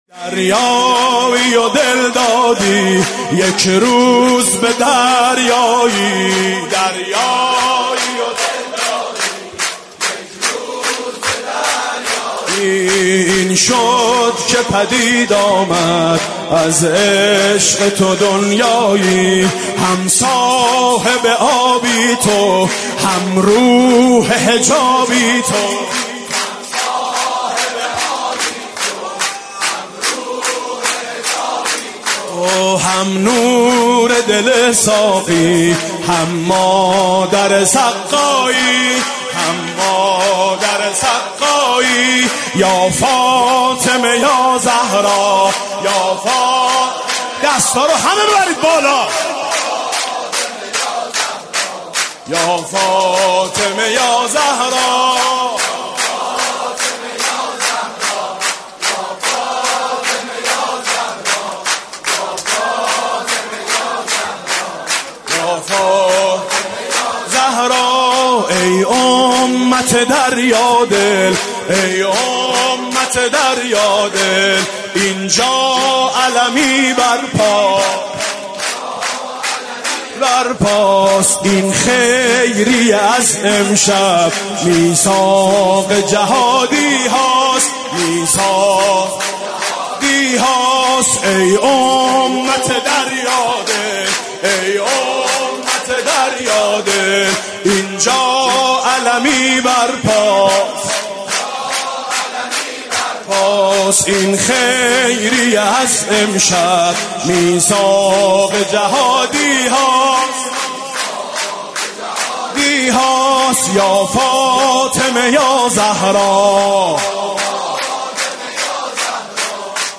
مداحی آنلاین